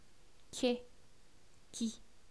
Consonnes - Sujet #1
che chi
che_chi1_[16b].wav